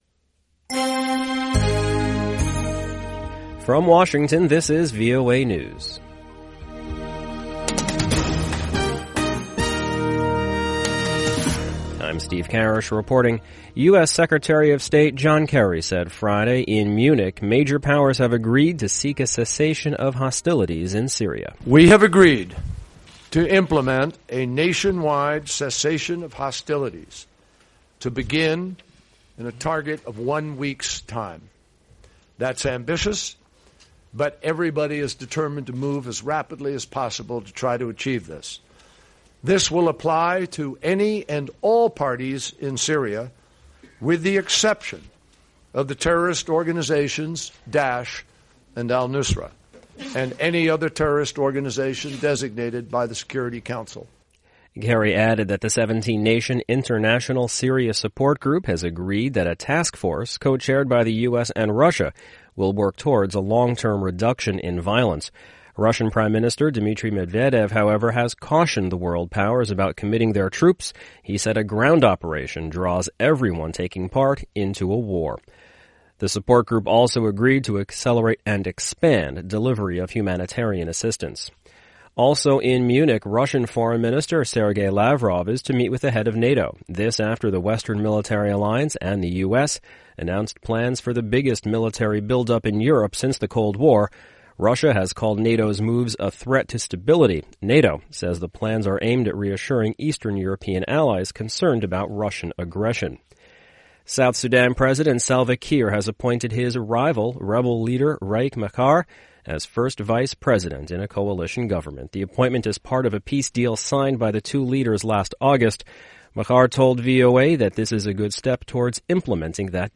VOA English Newscast: 1300 UTC February 12, 2016